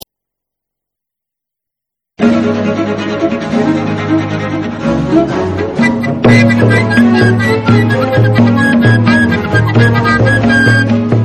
baroque Christmas tunes